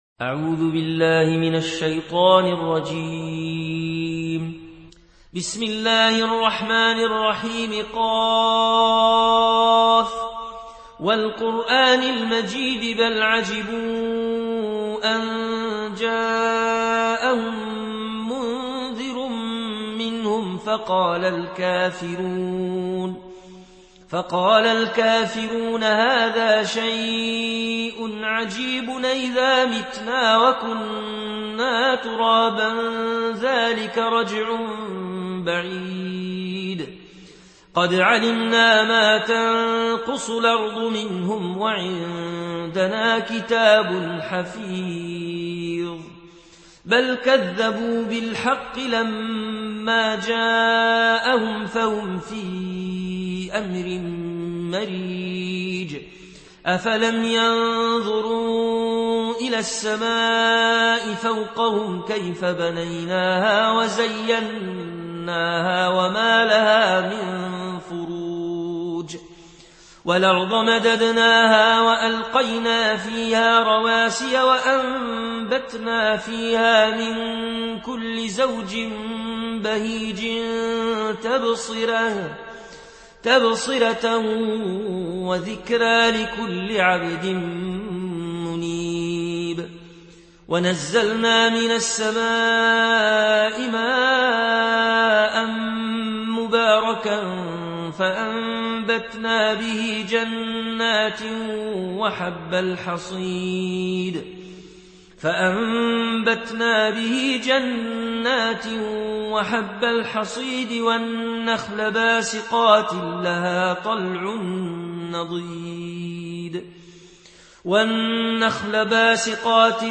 Riwayat Warsh an Nafi